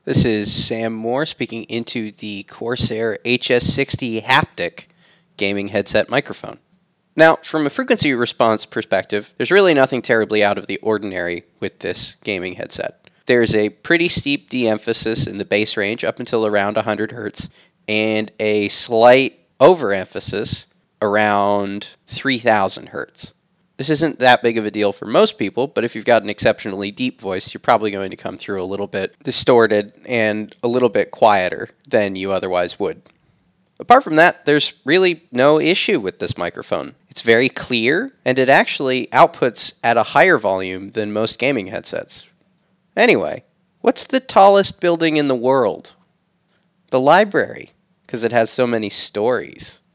Corsair-HS60-Haptic-mic-sample.mp3